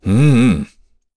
Chase-Vox-Deny_kr.wav